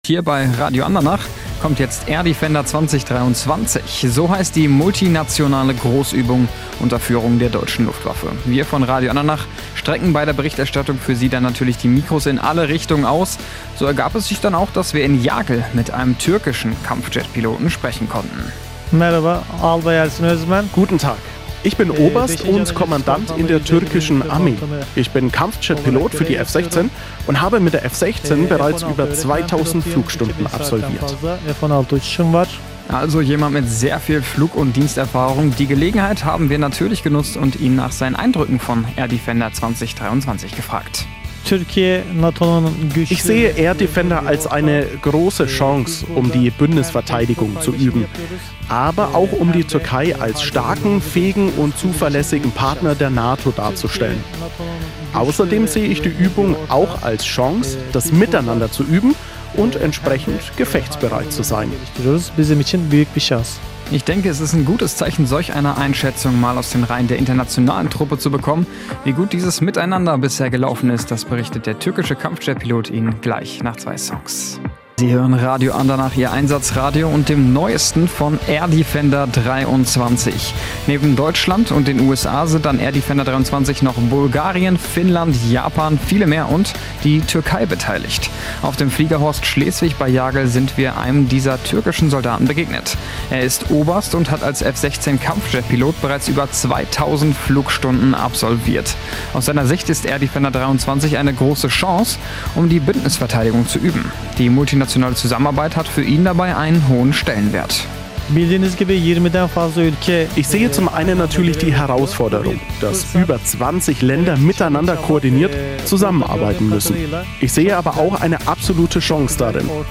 Audio türkischer Pilot Air Defender 2023
2 Soldaten im Interview vor Kampfflugzeug